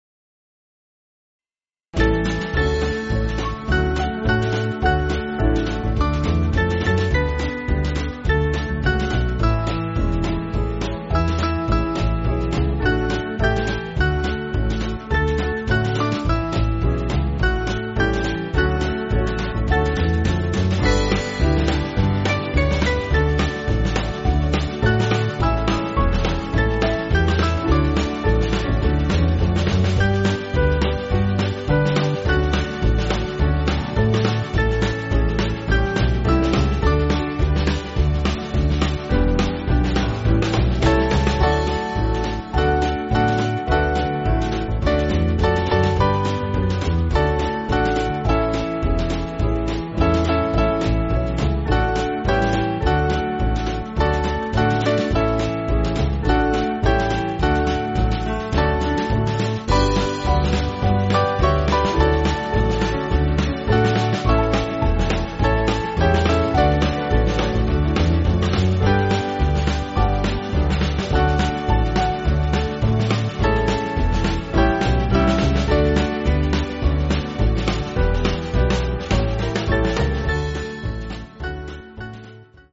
Small Band
(CM)   4/D-Eb